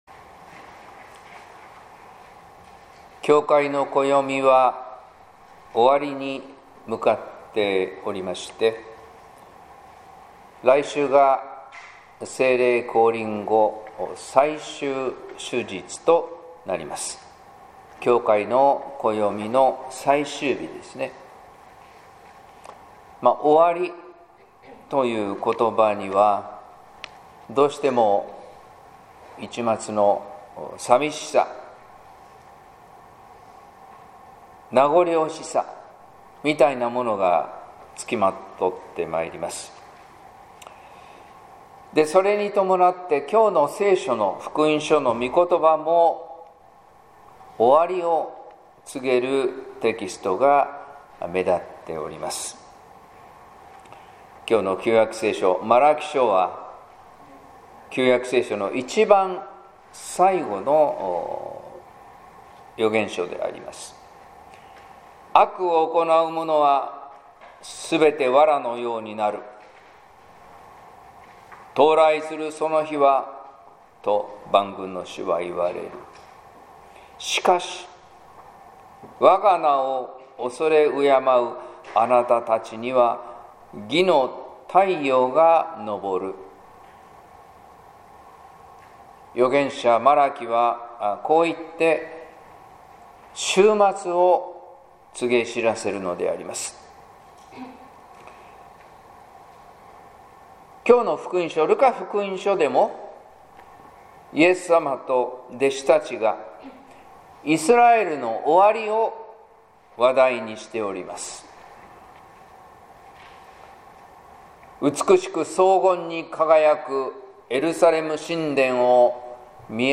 説教「いのちを生き抜く」（音声版）